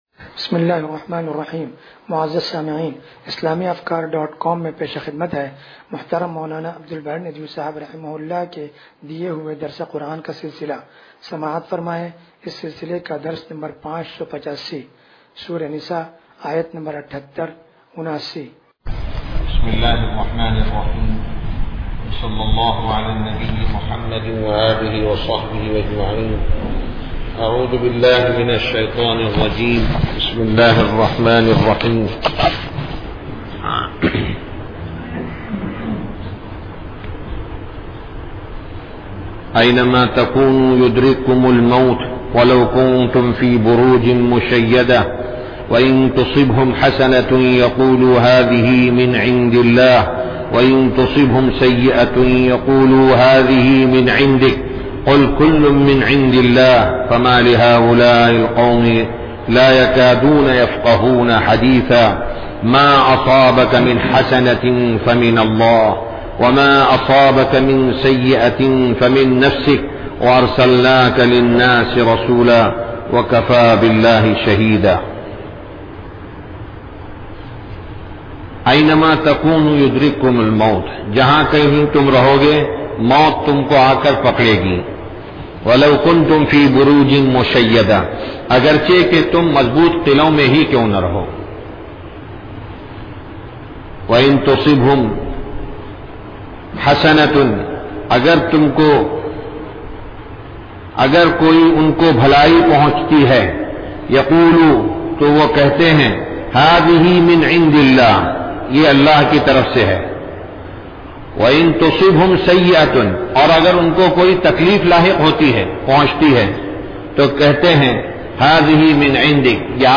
درس قرآن نمبر 0585